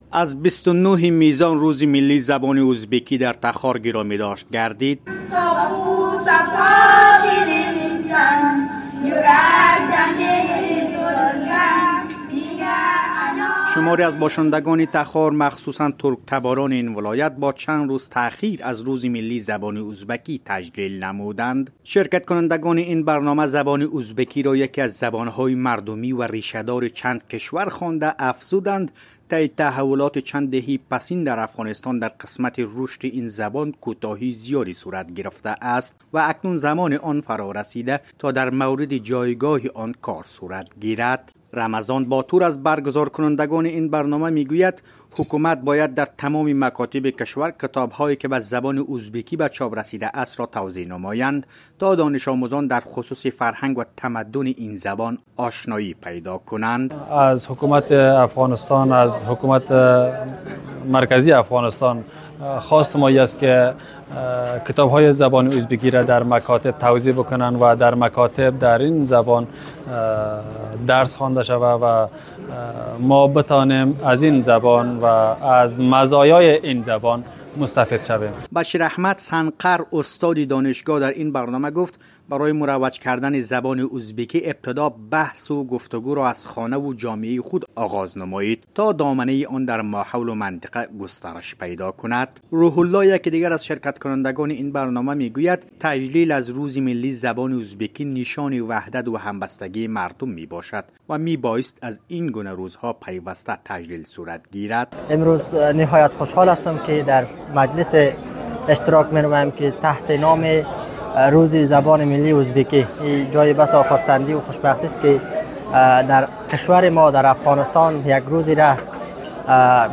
خبر رادیو